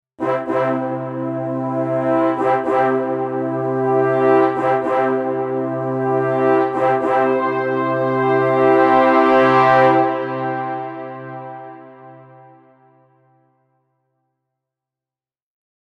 Elegant Brass Celebration Sound Effect
Elegant brass celebration sound effect. This gentle sound or musical phrase is ideal for winning or ceremonial moments.
Use it to add a subtle yet emotional boost to your project.
Elegant-brass-celebration-sound-effect.mp3